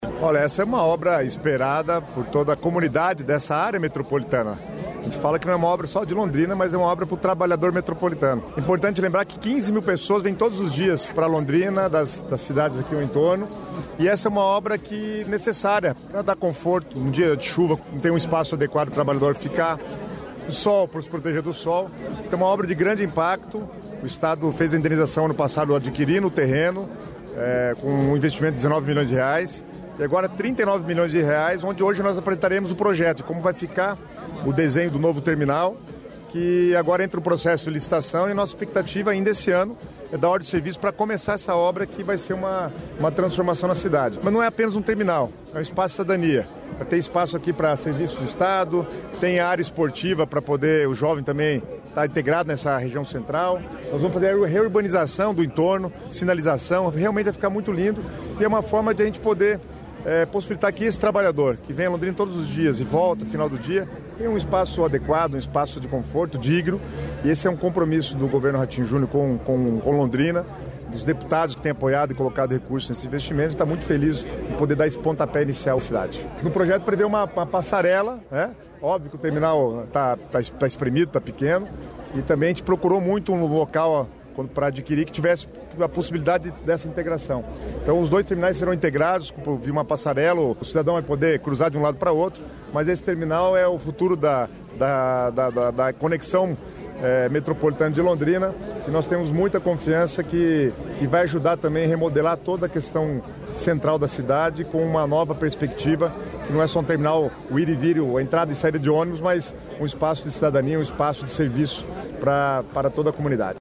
Sonora do secretário estadual das Cidades, Guto Silva, sobre projeto final do Terminal Metropolitano de Londrina